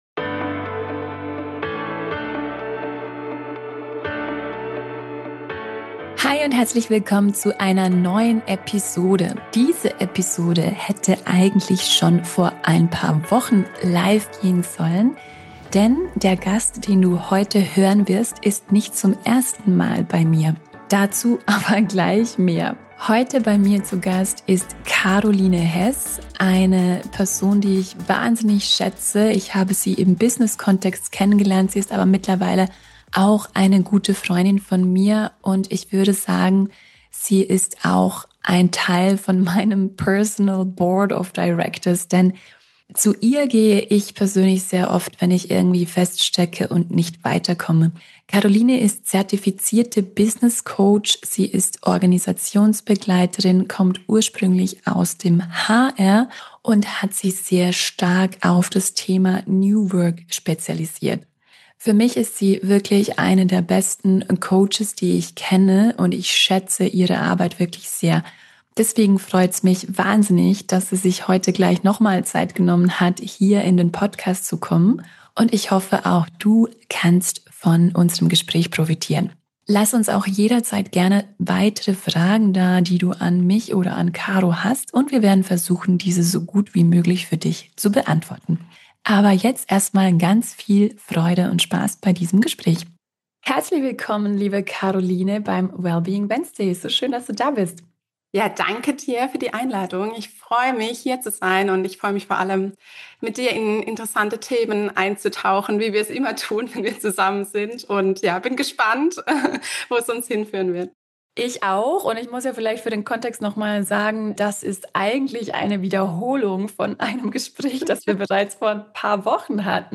Hier findest du das Original Podcastgespräch LIVE von der New Work Evolution in Karlsruhe Und wer bin ich?